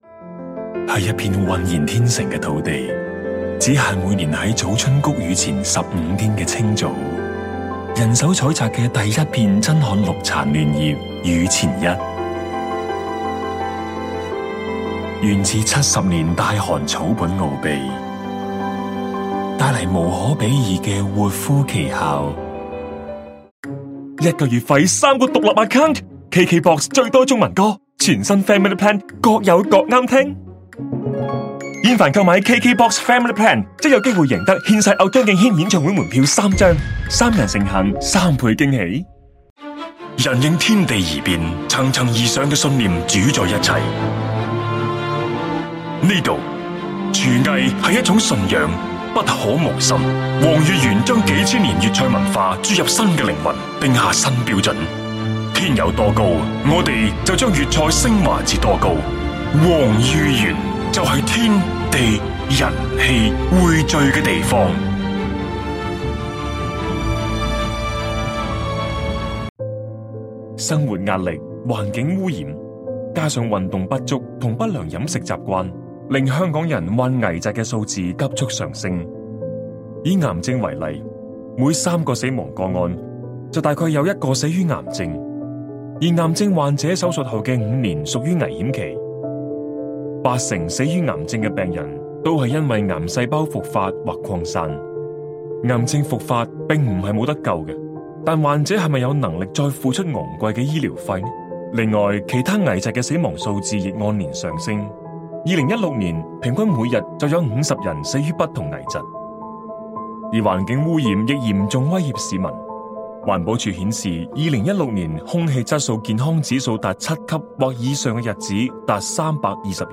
Kantonca (Honkong) Seslendirme
Erkek Ses